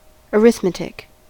arithmetic: Wikimedia Commons US English Pronunciations
En-us-arithmetic.WAV